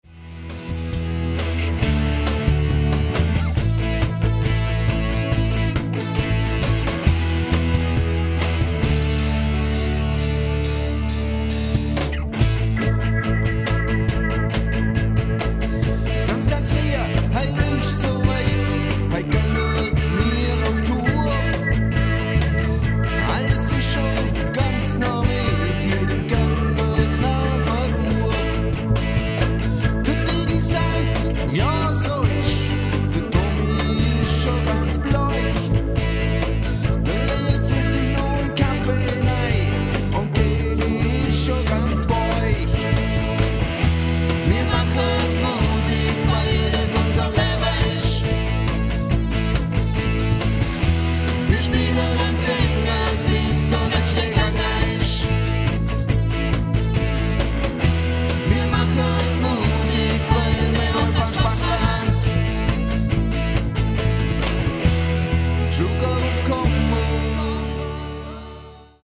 Alljährliches Open Air mit Schwoba-Rock-Bands seit 2000.
Drums
Bass
Keyboards
E-Gitarre
Congas,Gesang
Gitarre, Gesang
Percussion, Gesang